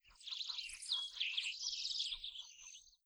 Birds 3.wav